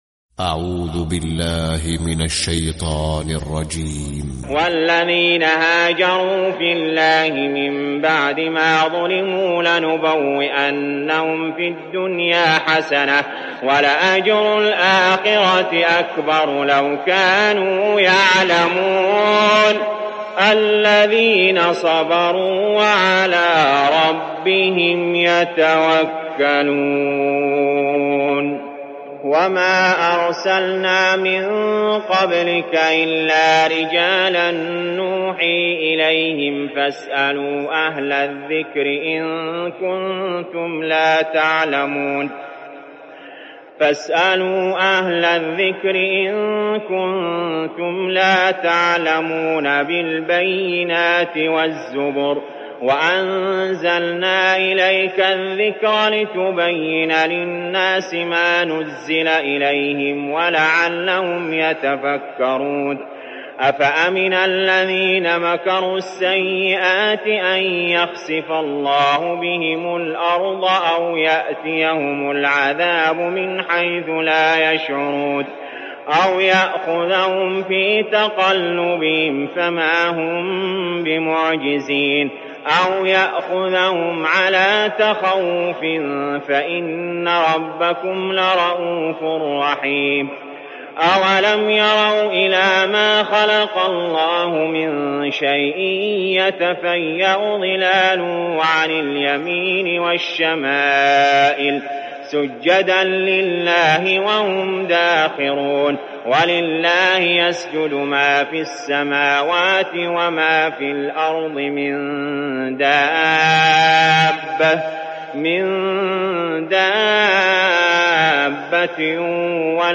🌾💛•تلاوة مميزة•💛🌾
👤القارئ : علي جابر